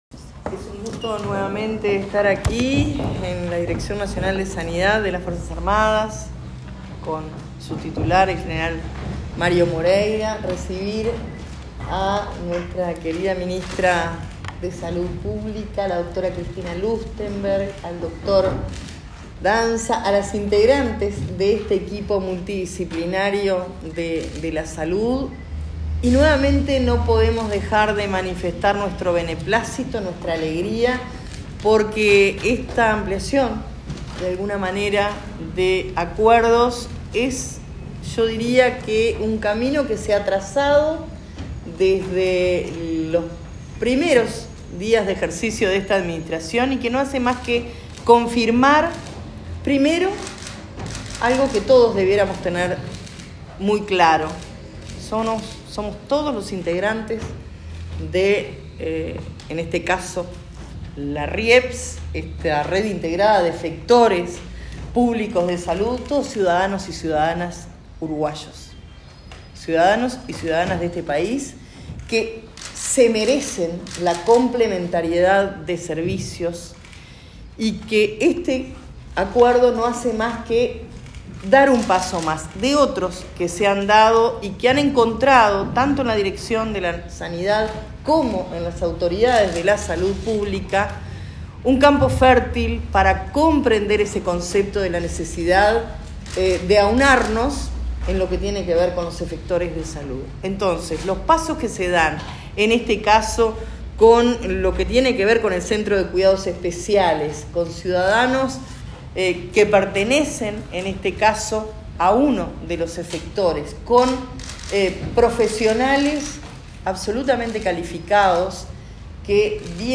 Palabras de autoridades de MDN, MSP, ASSE y Sanidad Militar en firma de acuerdo